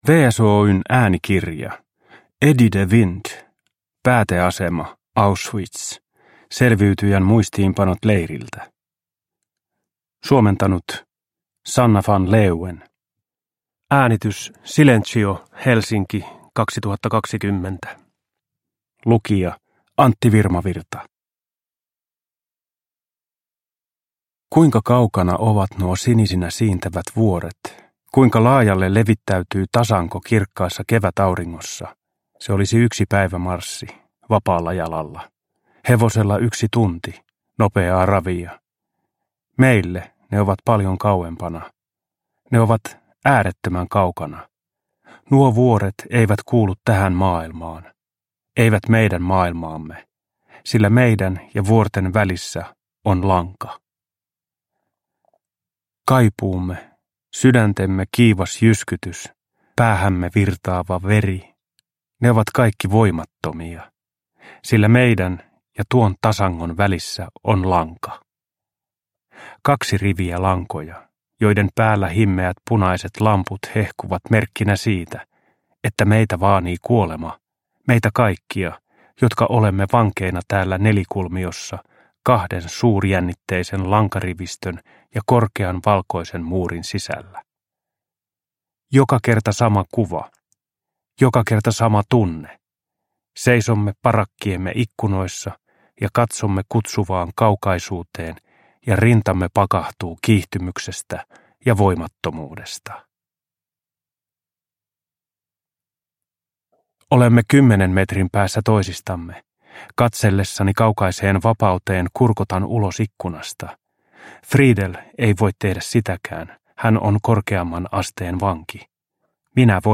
Pääteasema Auschwitz – Ljudbok – Laddas ner
Uppläsare: Antti Virmavirta